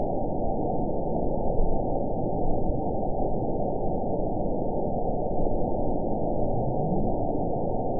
event 911308 date 02/22/22 time 03:42:31 GMT (3 years, 3 months ago) score 9.62 location TSS-AB01 detected by nrw target species NRW annotations +NRW Spectrogram: Frequency (kHz) vs. Time (s) audio not available .wav